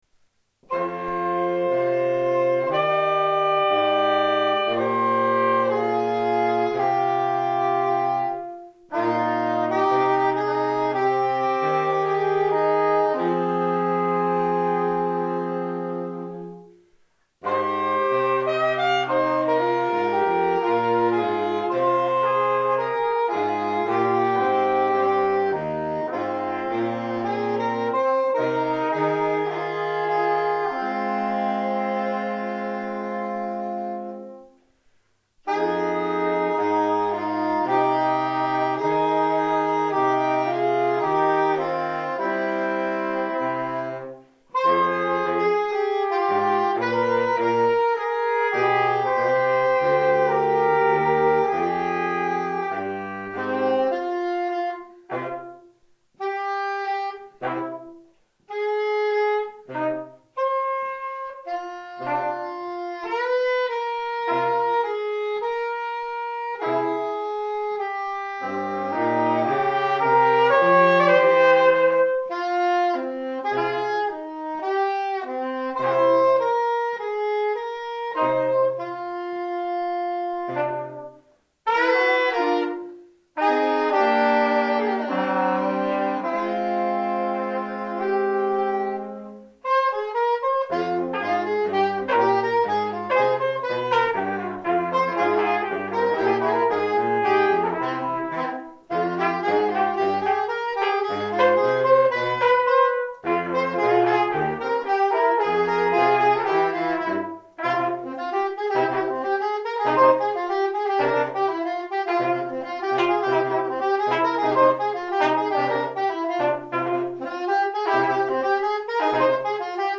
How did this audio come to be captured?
· Kanal-Modus: stereo · Kommentar